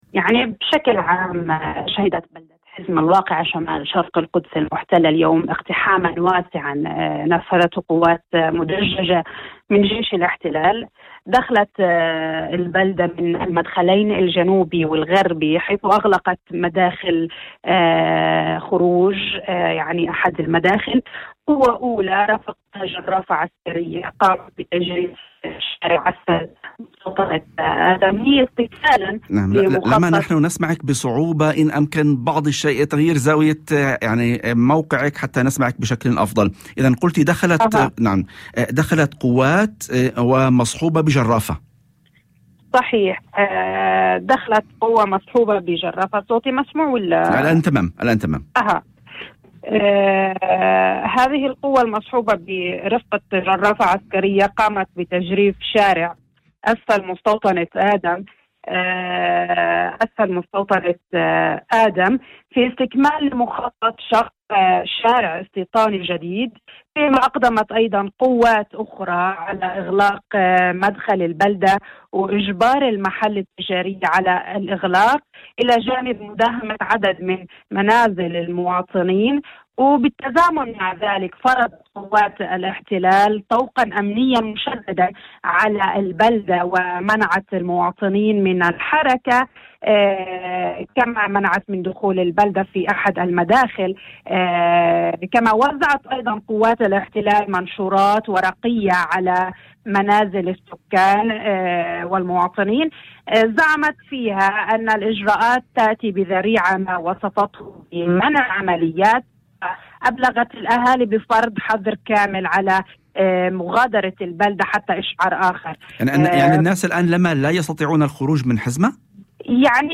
وأضافت في مداخلة هاتفية ضمن برنامج "يوم جديد"، على إذاعة الشمس، أن القوات أغلقت مدخل البلدة، وأجبرت أصحاب المحال التجارية على إغلاق محالهم، إلى جانب مداهمة عدد من منازل المواطنين.